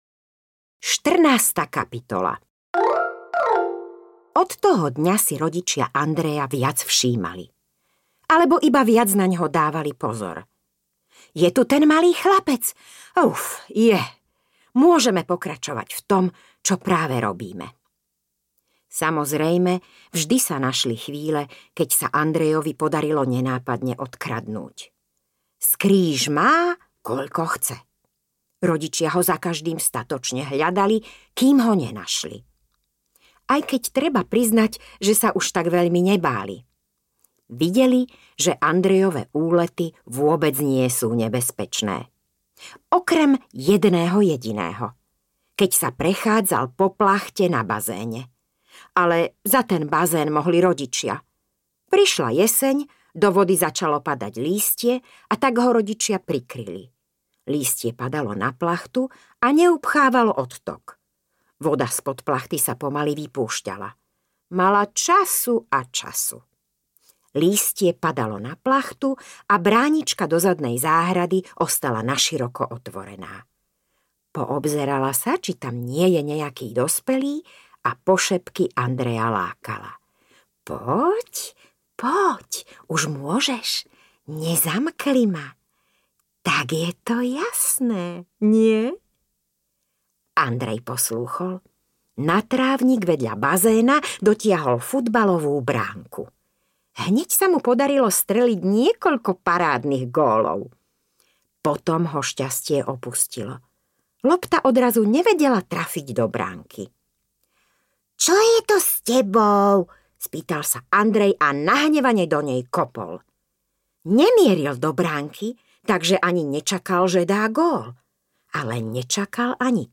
Túlavý braček audiokniha
Ukázka z knihy